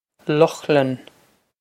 Lochlann Lokh-lun
Lokh-lun
This is an approximate phonetic pronunciation of the phrase.